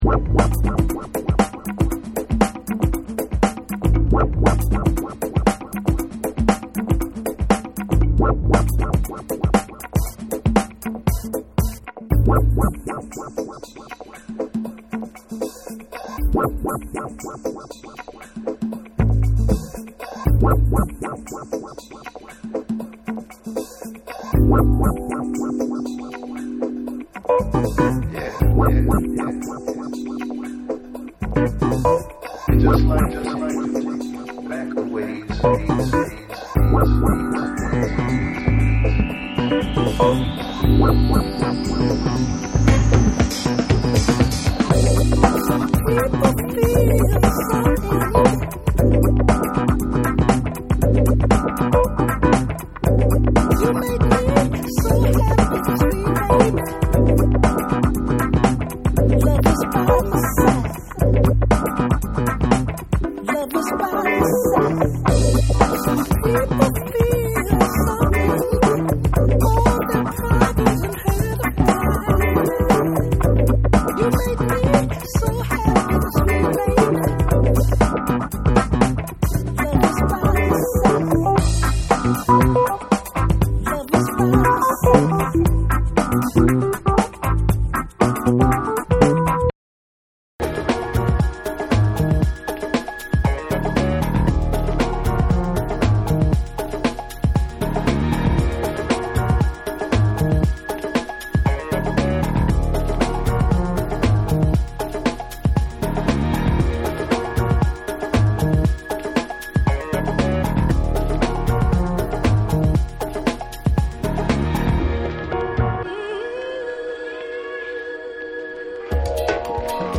ブレイクビーツとハウス・グルーヴが混ざり合ったリズムにファンキー でウォーミーなベース & エレピなどが絡み
TECHNO & HOUSE